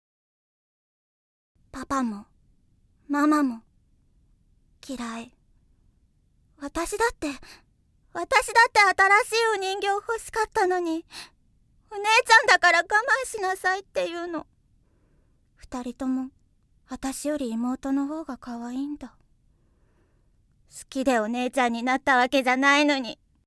◆不機嫌な少女◆